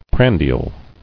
[pran·di·al]